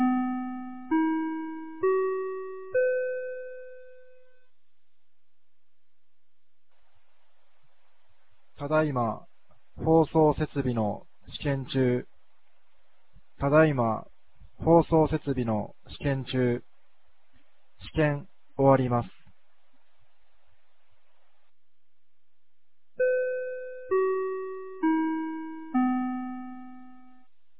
2025年08月09日 16時03分に、由良町から全地区へ放送がありました。